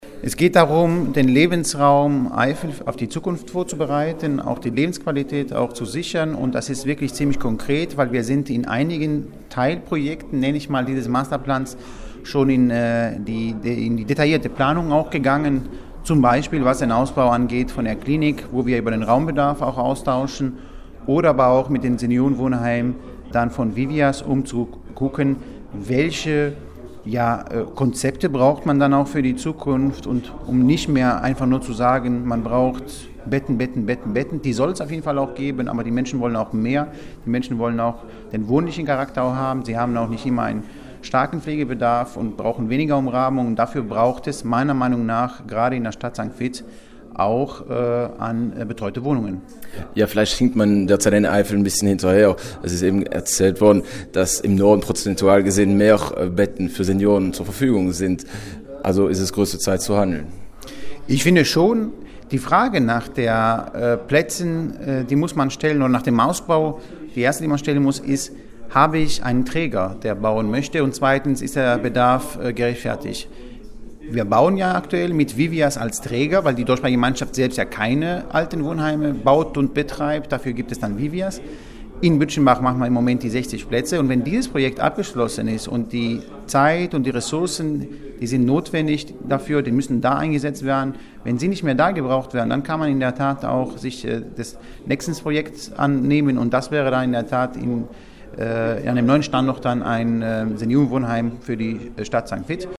Dazu der zuständige Minister Antonios Antoniadis im Gespräch